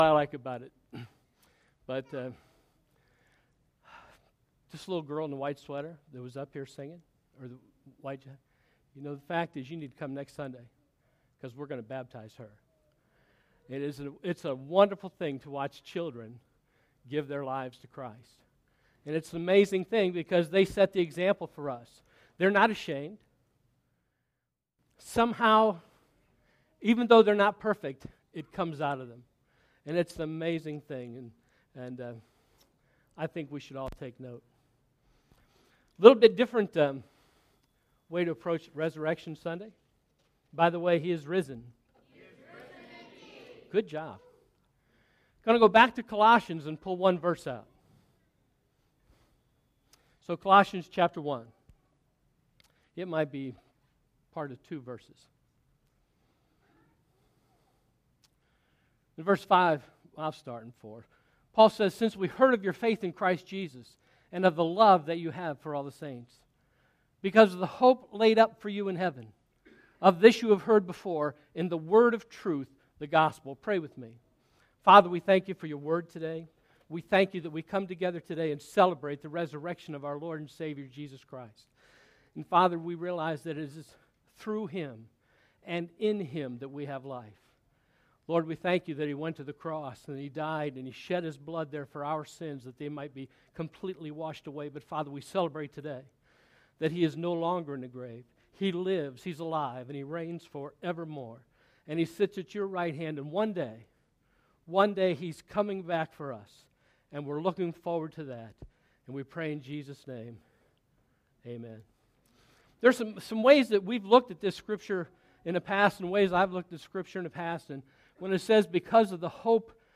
First Baptist Church of Gahanna, OH Sermons